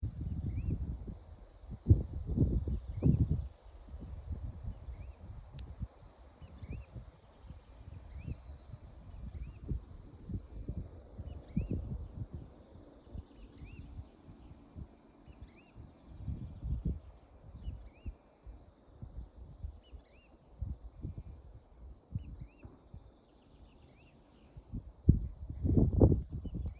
Sila cīrulis, Lullula arborea
StatussDzirdēta balss, saucieni